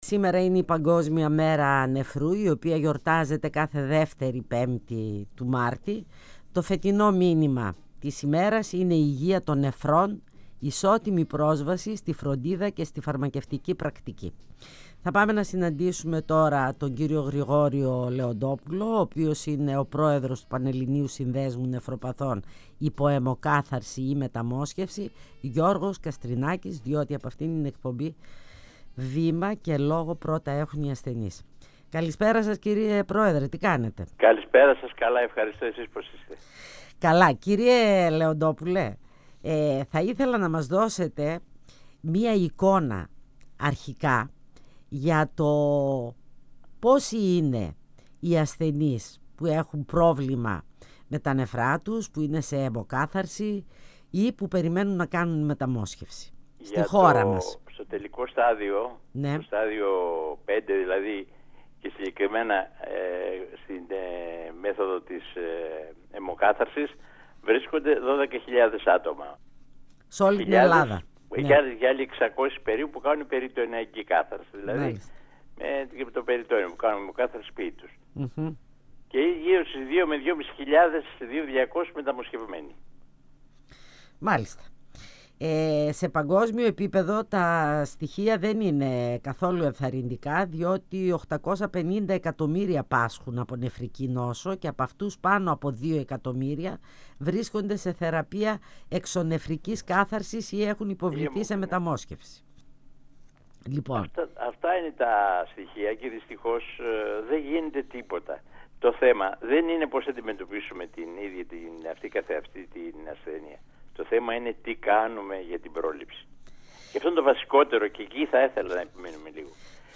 102FM Φωνες Πισω απο τη Μασκα Συνεντεύξεις